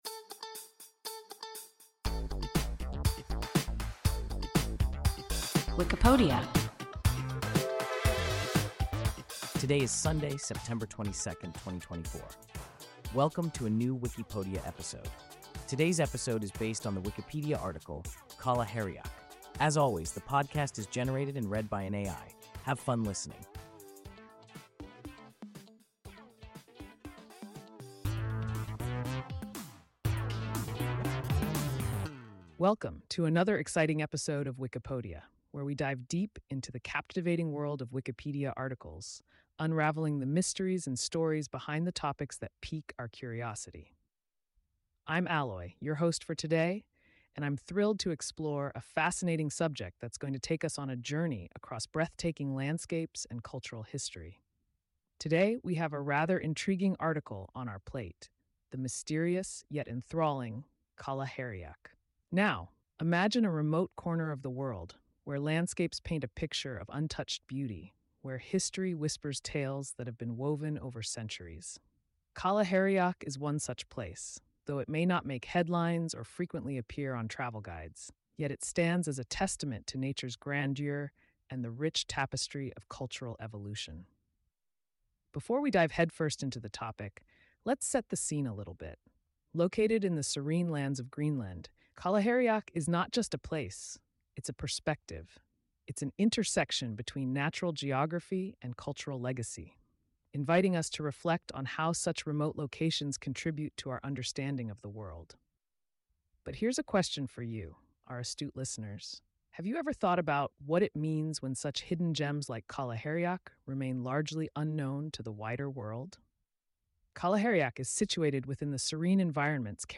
Qalaherriaq – WIKIPODIA – ein KI Podcast